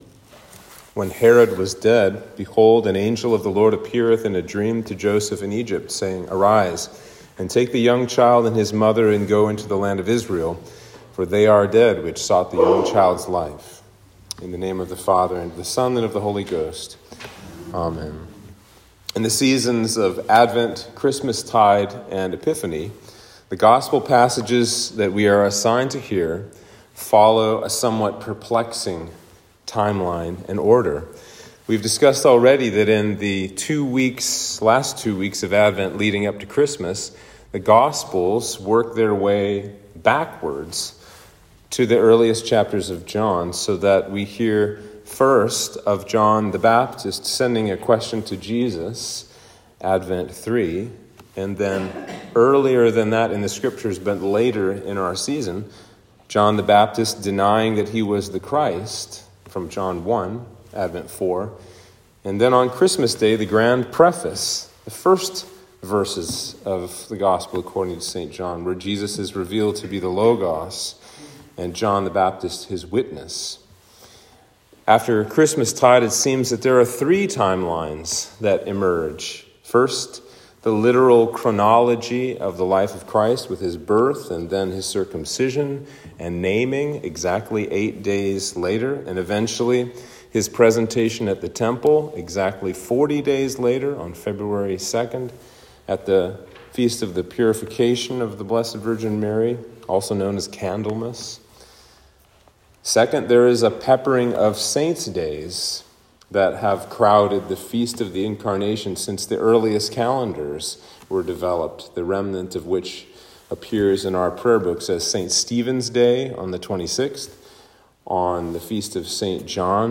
Sermon for Christmas 2